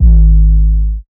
Southside 808 (1).wav